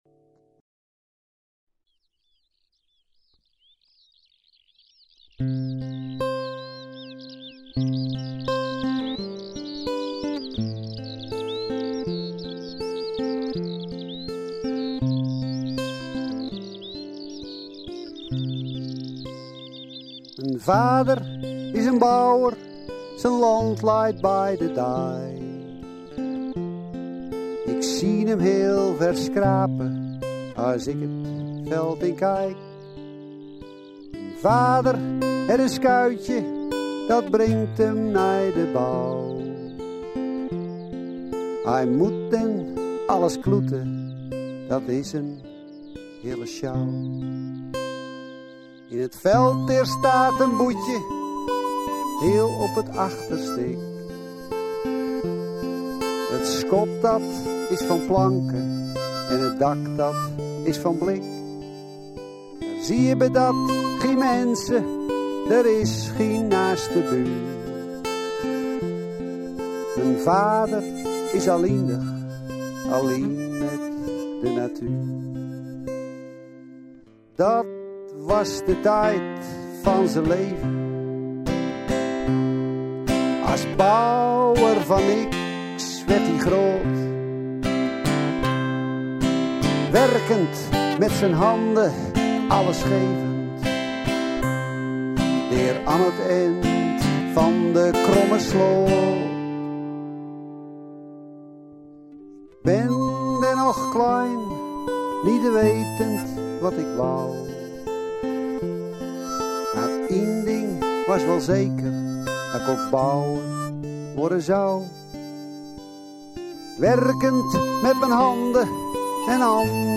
Het melancholieke nummer
Westfrieslanpop